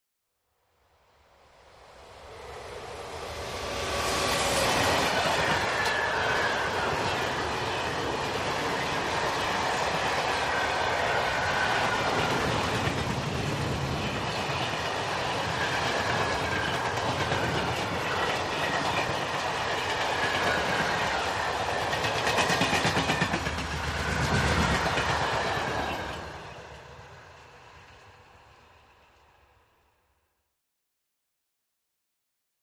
Electric Train ( European ), By Very Fast, Cu with Rail Noise.